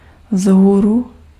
Ääntäminen
IPA: [ɔp]